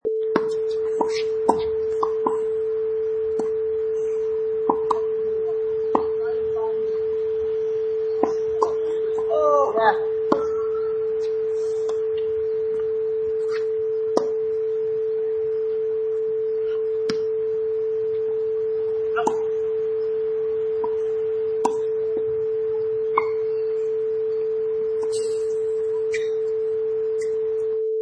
Wav: Tennis Volley 1
Tennis volley with ambient noise at park
Product Info: 48k 24bit Stereo
Category: Sports / Tennis
Try preview above (pink tone added for copyright).
Tennis_Volley_1.mp3